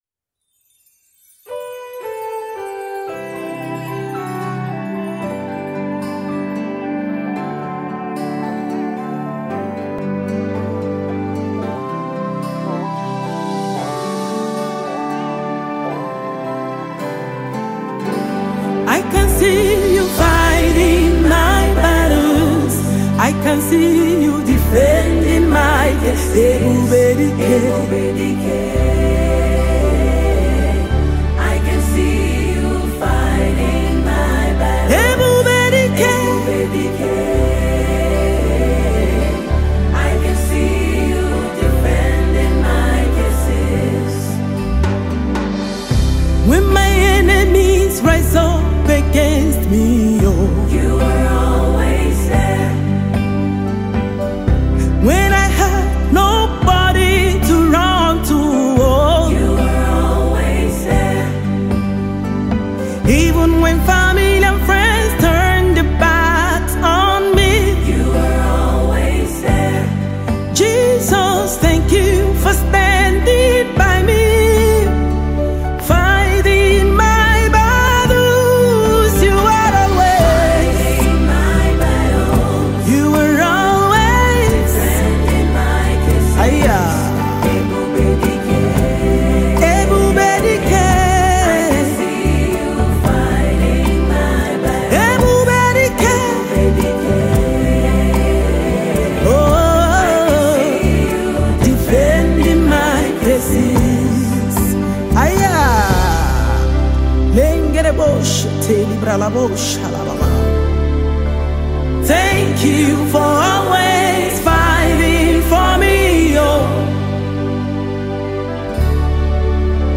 Amazing gospel music minister with a wide vocal prowess